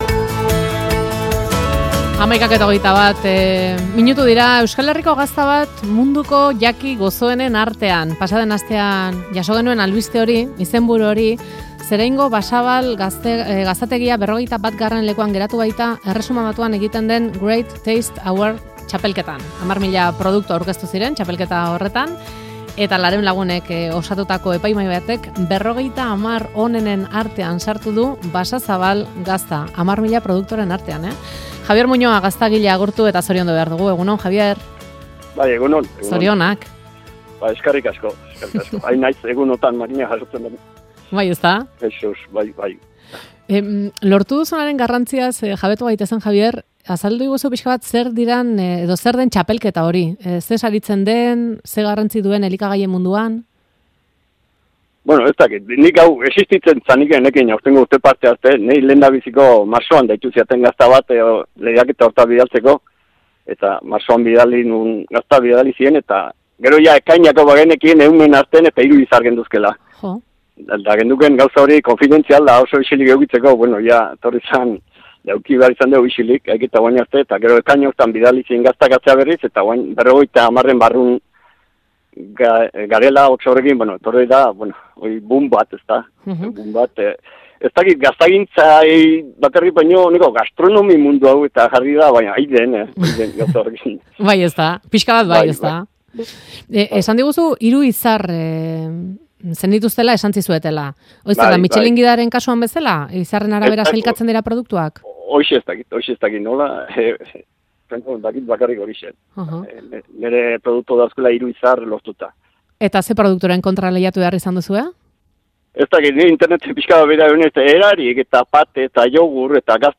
Euskadi Irratiko Faktorian elkarrizketa: Ingalaterrako Great Taste Awards munduko 50 jaki onenen artean sartu dute Zeraingo Basazabal gazta.